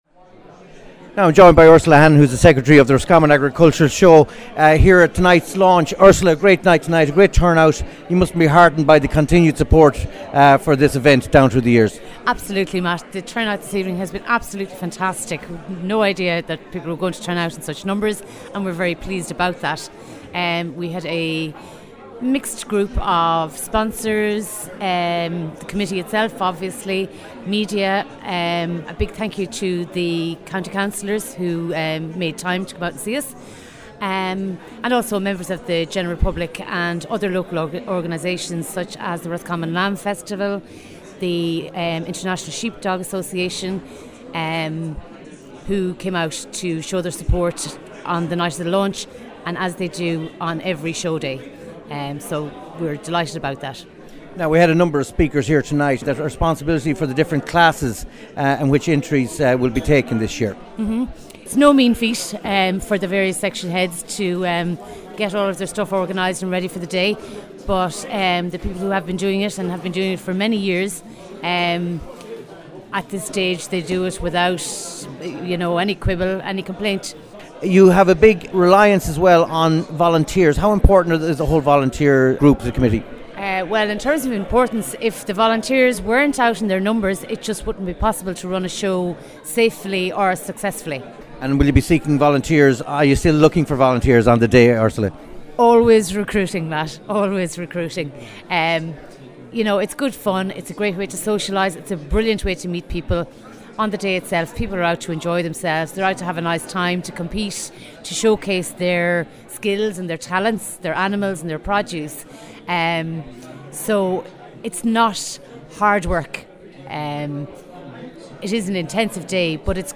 Roscommon Show Launch Interview